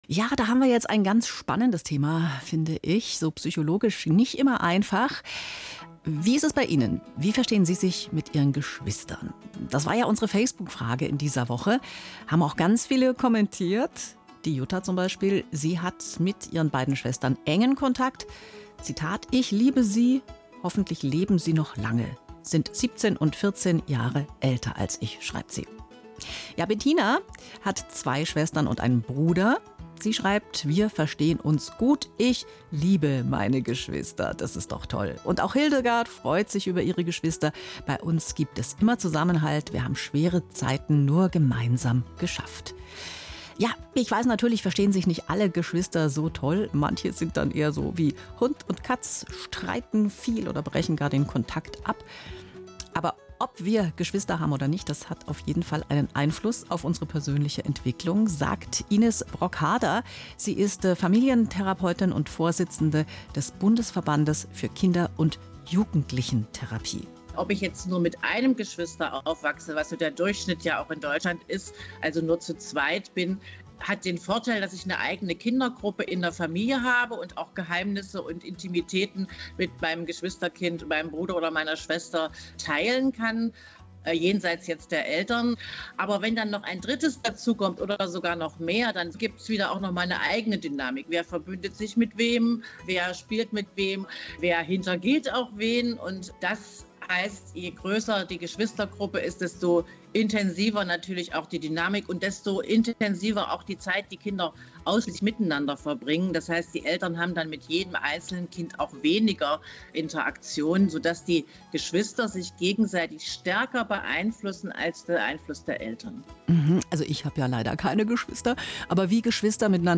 Interviews
Interviews Bayrischer Rundfunk zum Tag des mittleren Geschwisterkindes am 12.08.2025